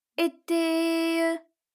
ALYS-DB-003-FRA - Source files of ALYS’ first publicly available French vocal library, initially made for Alter/Ego.